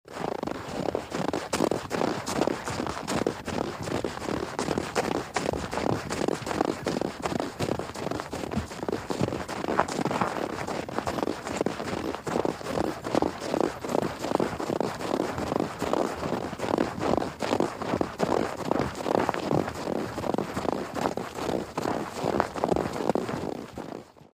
Звуки шагов по снегу
Быстрый темп